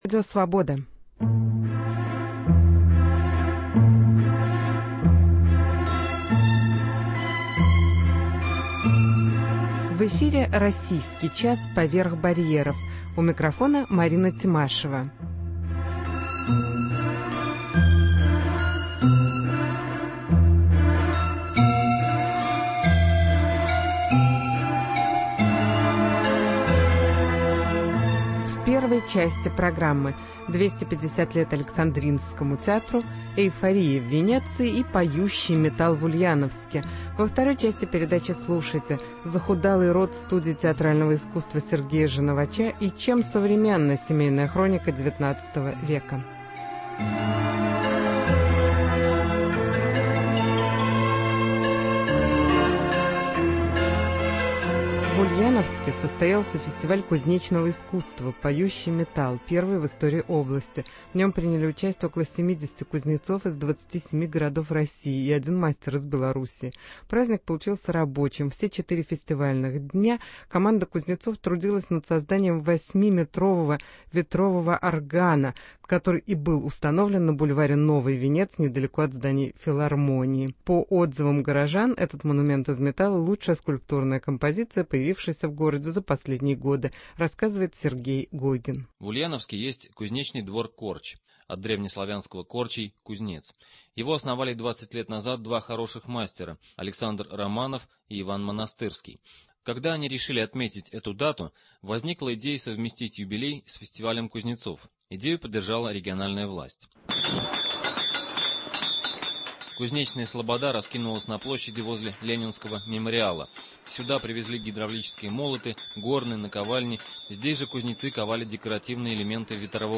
Интервью с Иваном Вырыпаевым , автором фильма-участника конкурса Венецианского фестиваля "Эйфория".